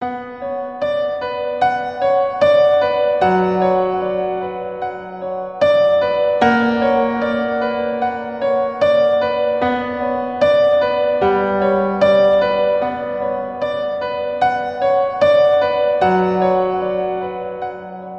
creepy-piano-meme-sound